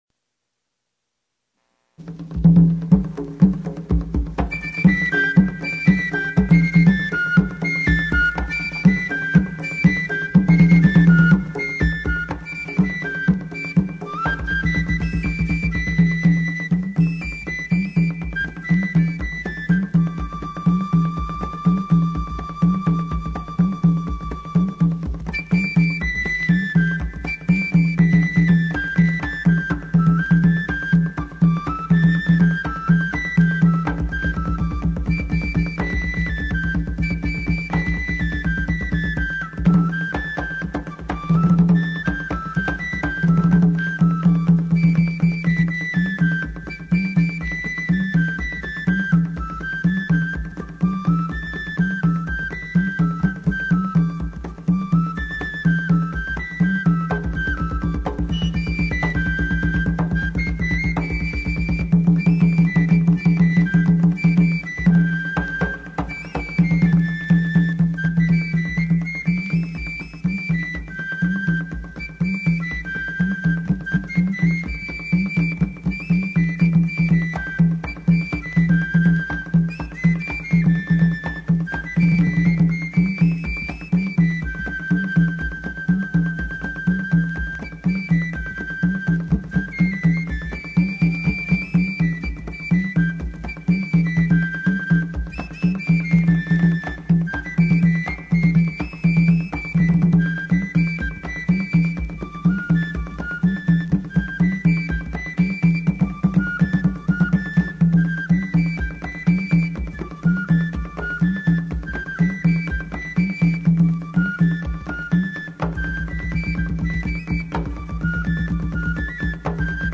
Contre danse flute et tambou 1
FLOKLORE HAITIEN
contre-danse-flute-et-tambou-1.mp3